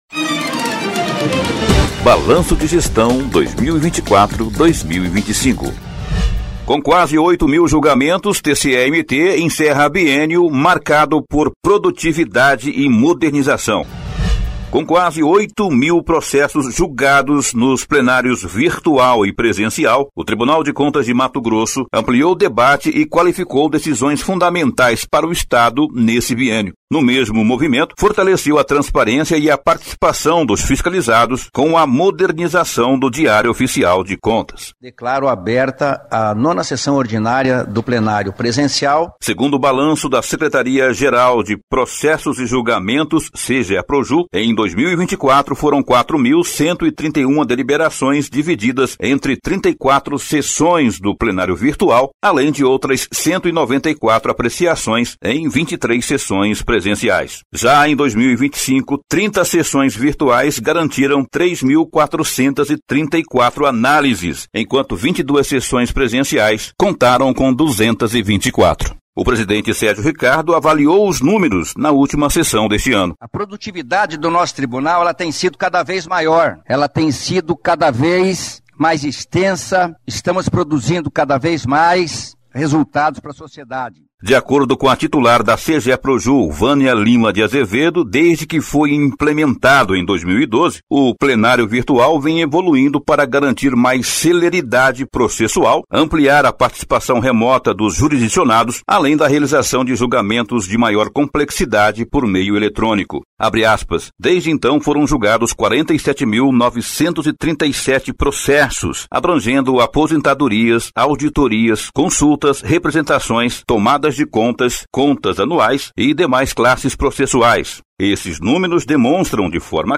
Sonora: Sérgio Ricardo – conselheiro-presidente do TCE-MT